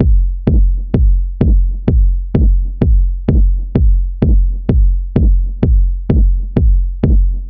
• house - techno beat passage - Fm - 128.wav
A loop that can help you boost your production workflow, nicely arranged electronic percussion, ready to utilize and royalty free.
house_-_techno_beat_passage_-_Fm_-_128_Hu3.wav